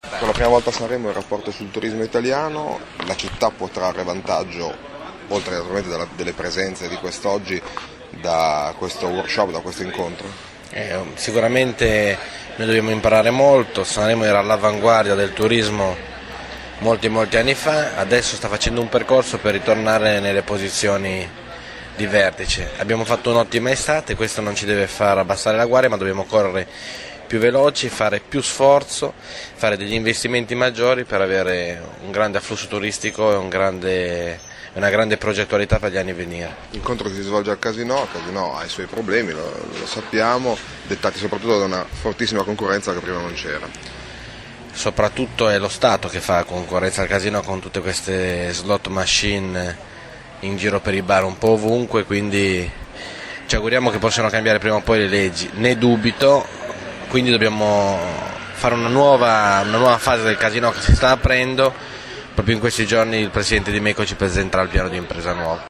Sanremo: gli interventi alla presentazione del 17° Rapporto del Turismo Italiano